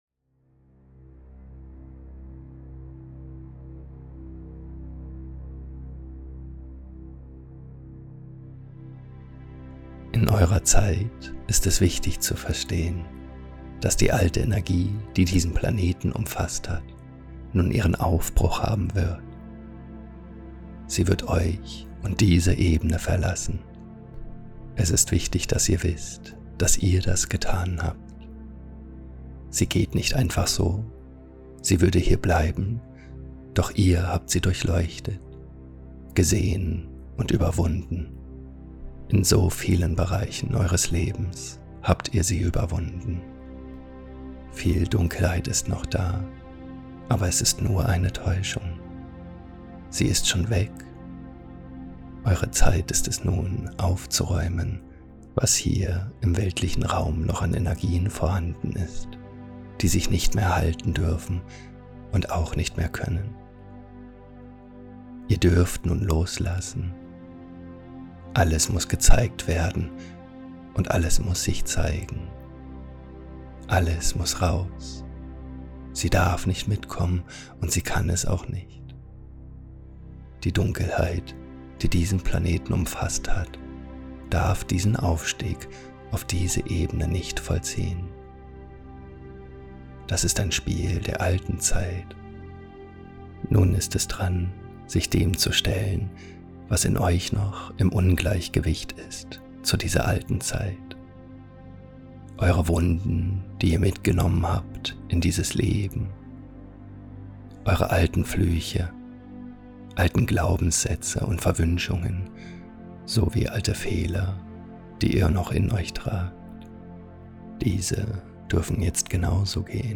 Channeling